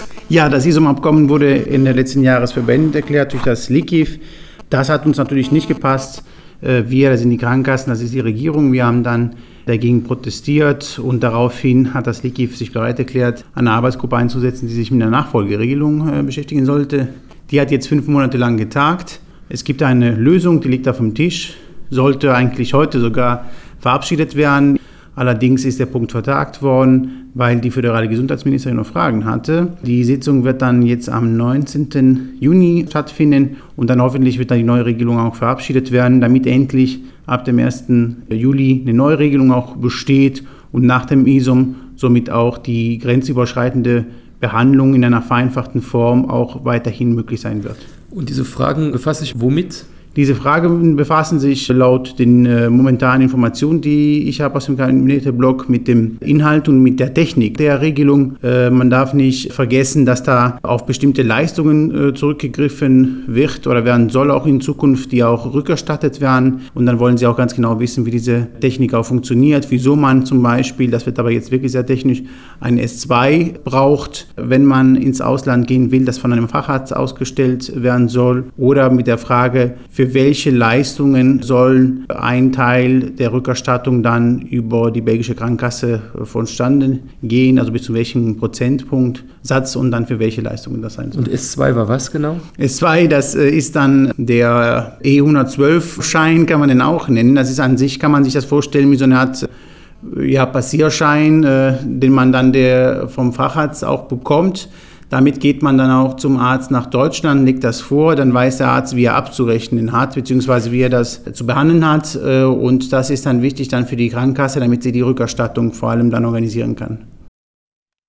sprach nach der Sitzung mit dem Minister über das IZOM-Abkommen: